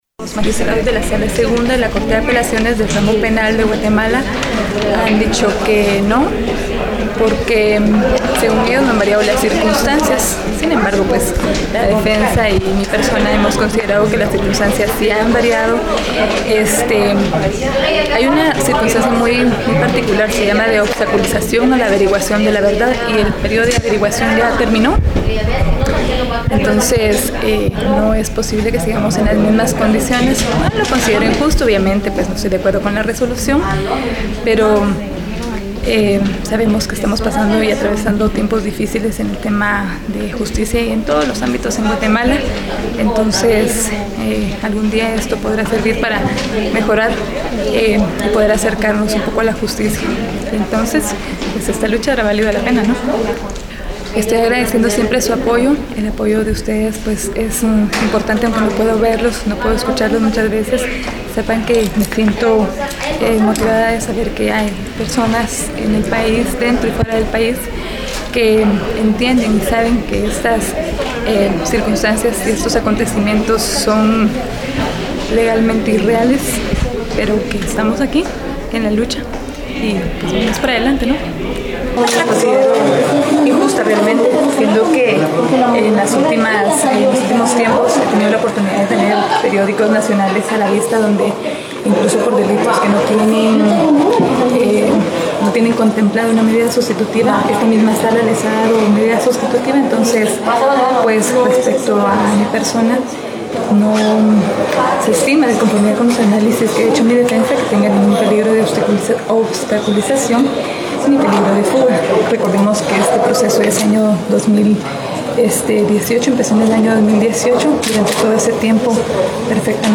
La propia Virgina Laparra, nos comentó luego de la audiencia:
Virginia-Laparra-luego-de-audiencia.mp3